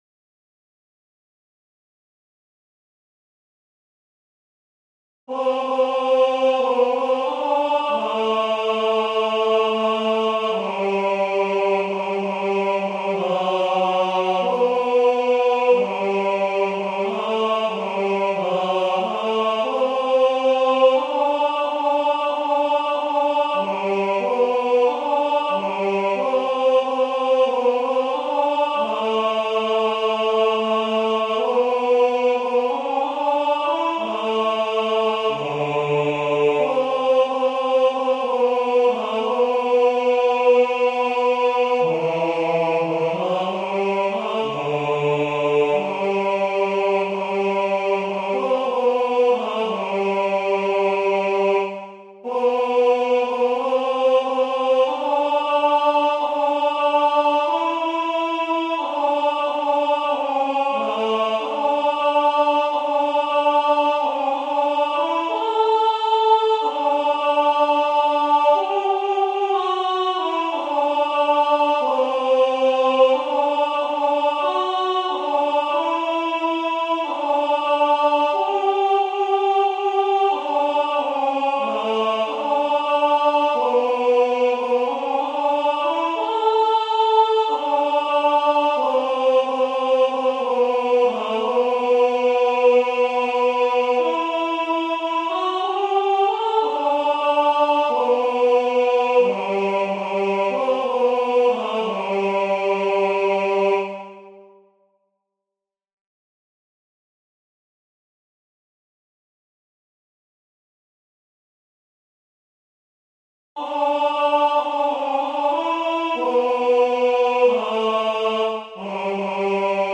音とり音源
テノール